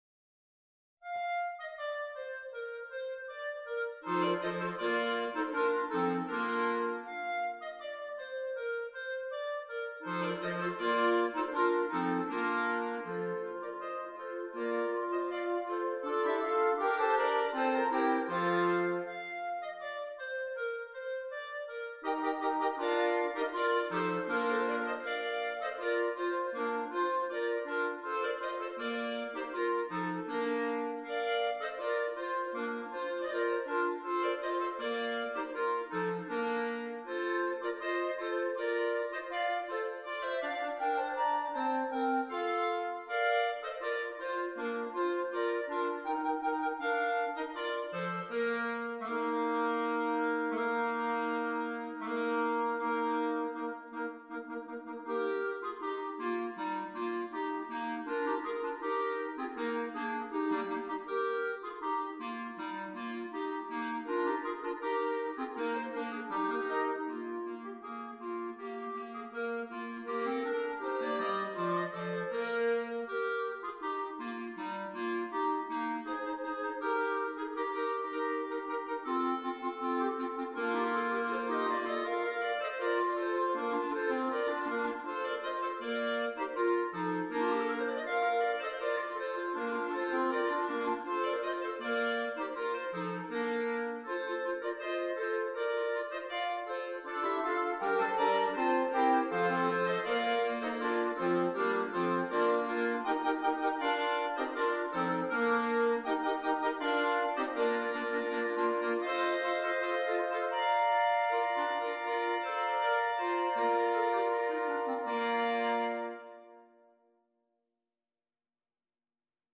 6 Clarinets
Traditional Carol